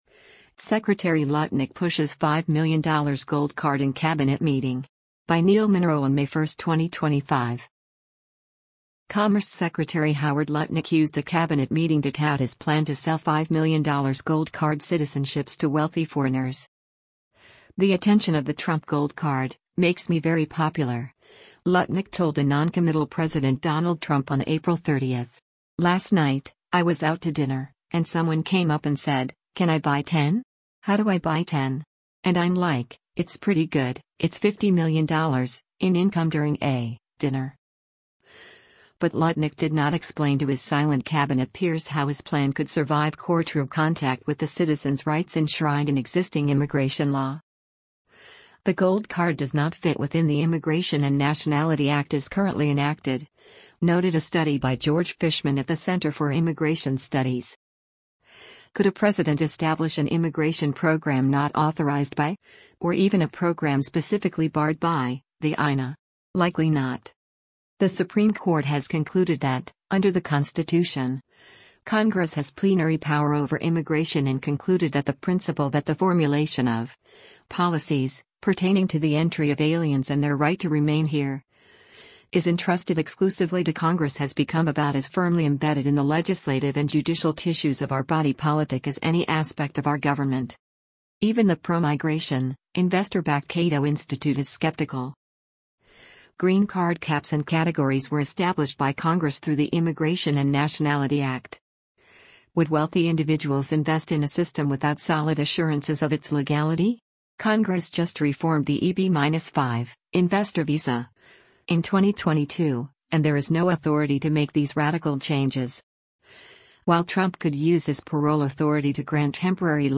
Sec. Lutnick Pushes $5 Million 'Gold Card' in Cabinet Meeting
Commerce Secretary Howard Lutnick during a Cabinet meeting at the White House on April 30,